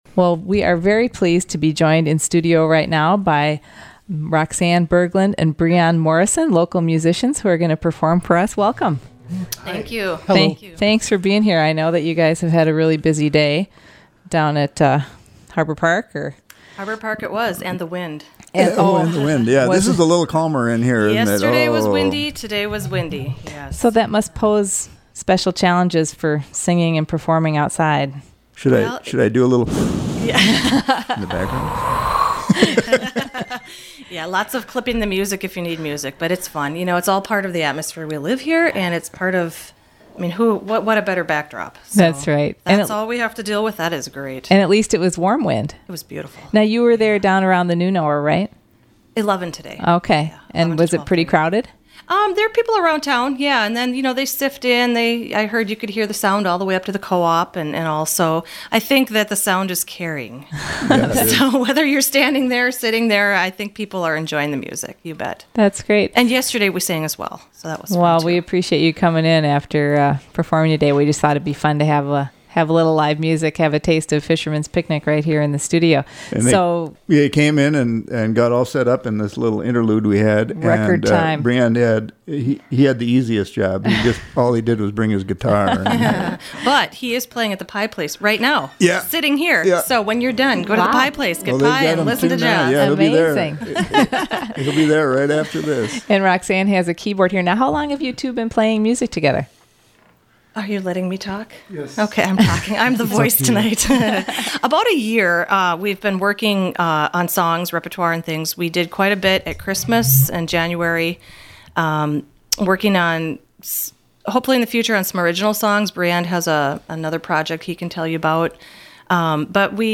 keyboard, vocals
guitar) to Studio A recently